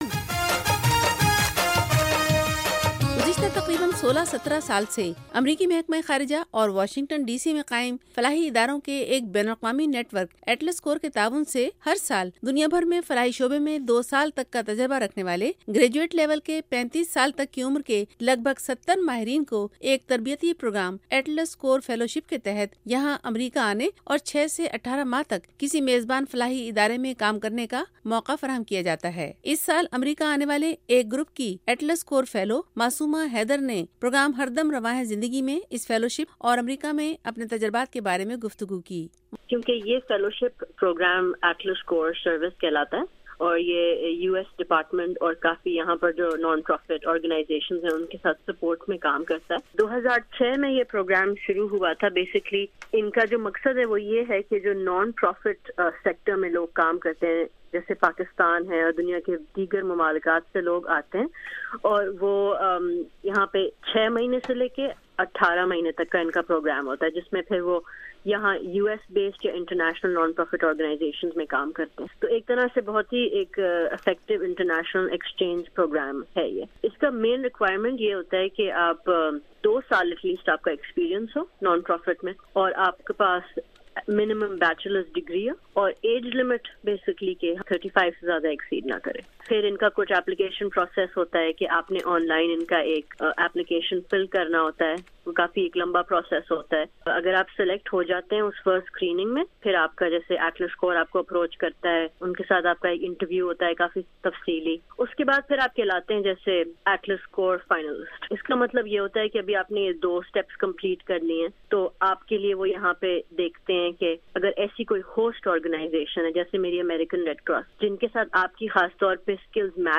انٹرویو اٹلس کور فیلو ۔۔۔